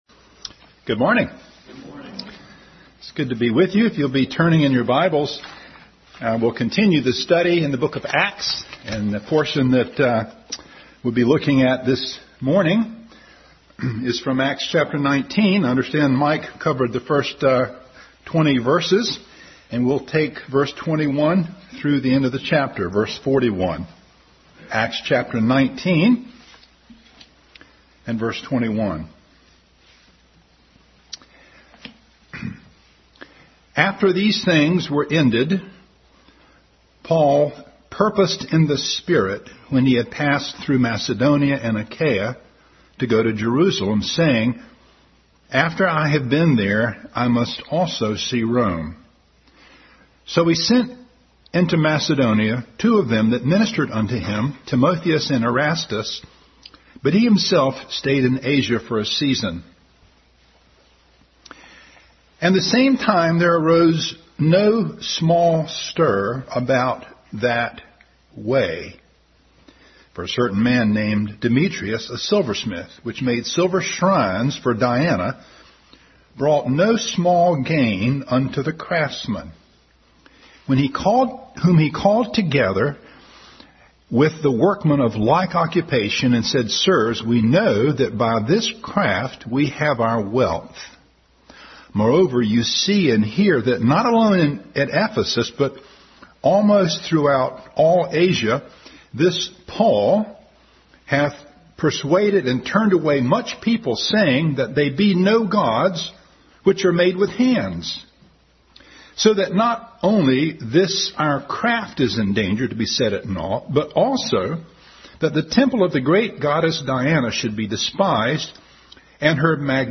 Acts 19:21-41 Service Type: Sunday School Bible Text